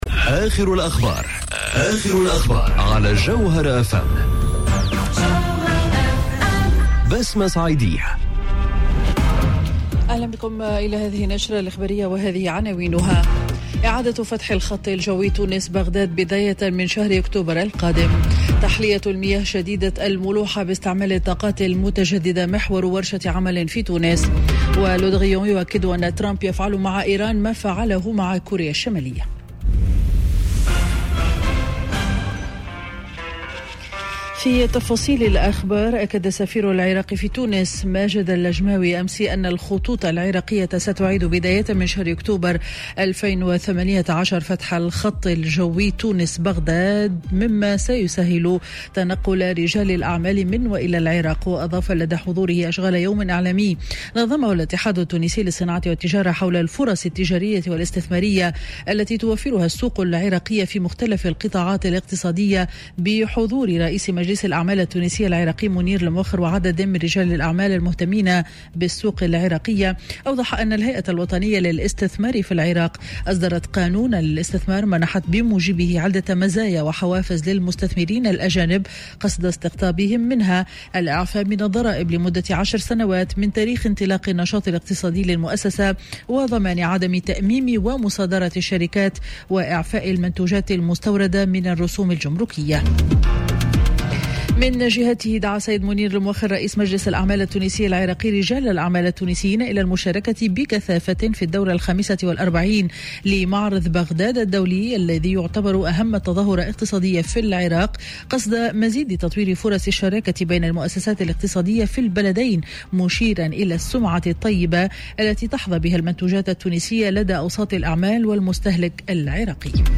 نشرة أخبار منتصف النهار ليوم الإربعاء 01 أوت 2018